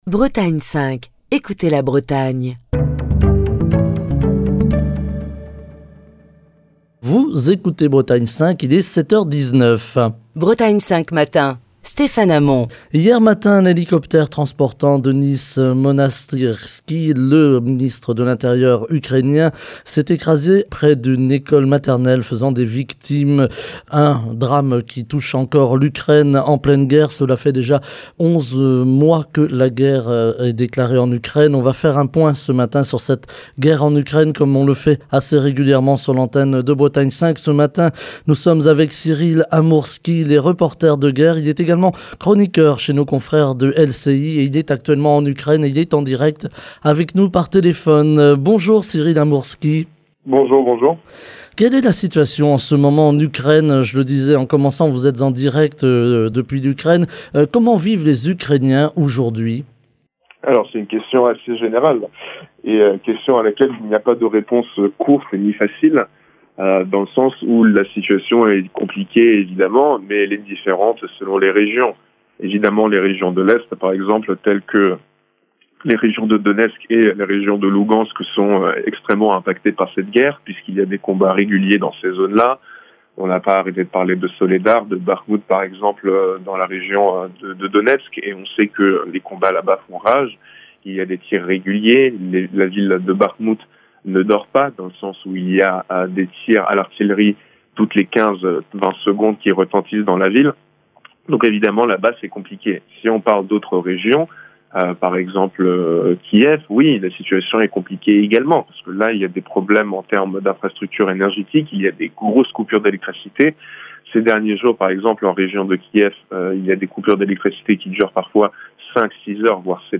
en direct depuis l'Ukraine